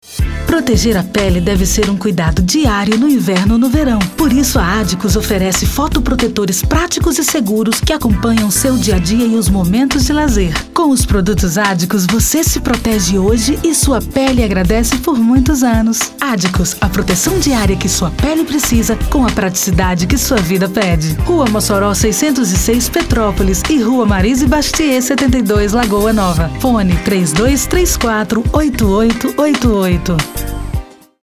Spots e vinhetas
Seja com locução feminina, masculina, interpretativa ou caricata, o spot vai desde o rádio ao podcast levando, de forma clara e objetiva, sua mensagem para o seu público-alvo.